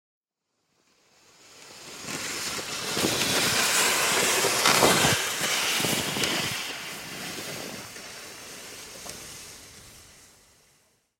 Звуки санок
Звук проезжающих санок — 2 вариант